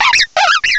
cry_not_ambipom.aif